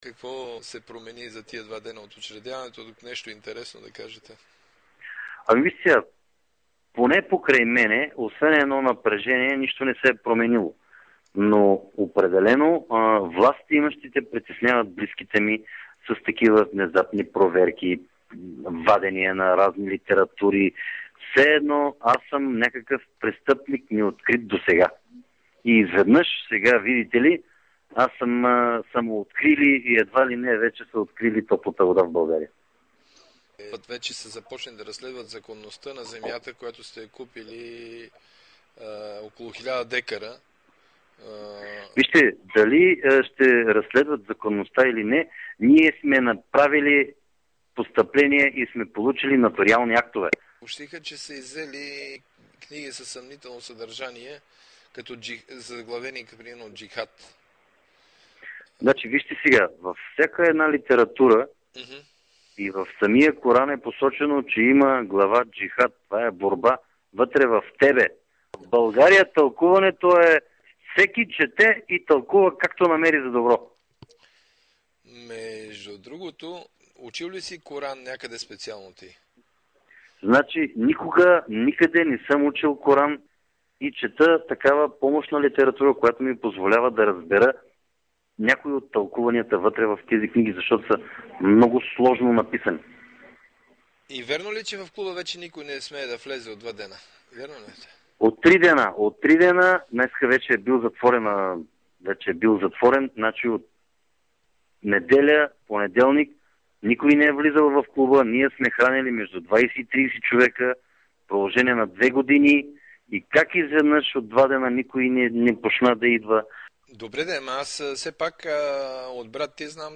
Разговорът